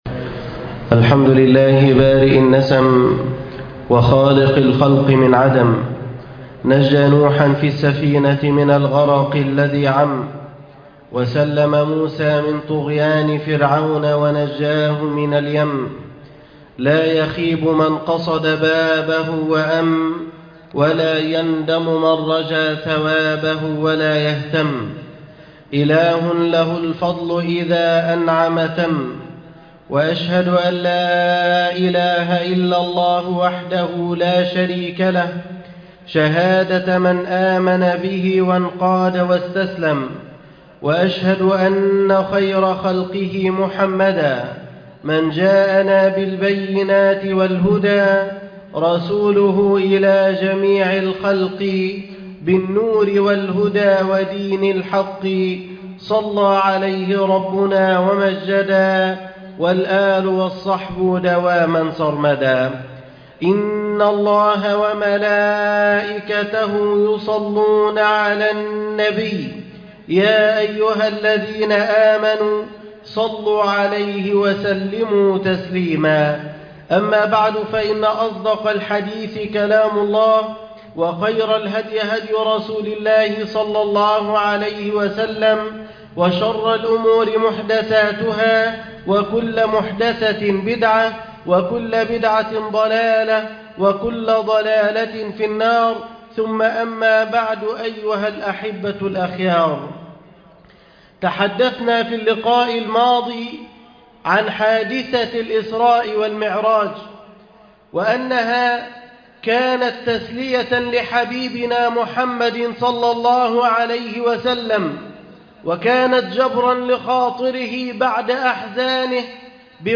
في ظلال السيرة النبوية الخطبة العاشرة